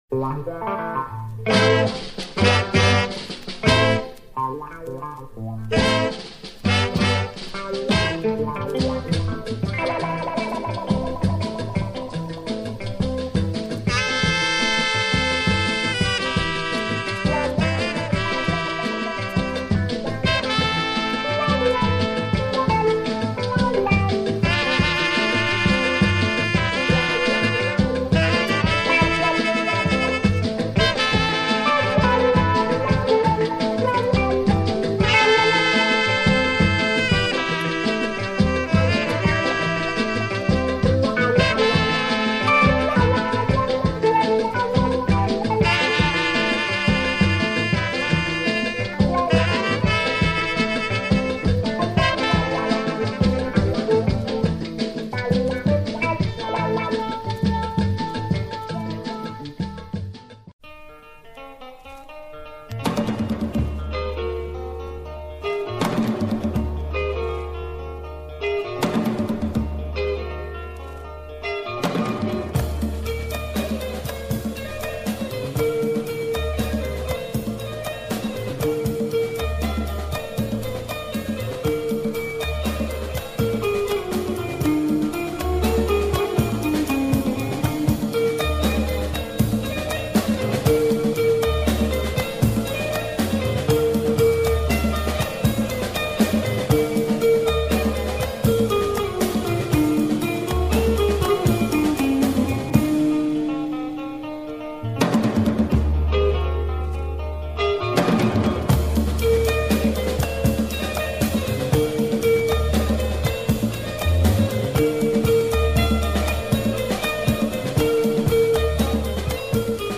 african jazz and afro beat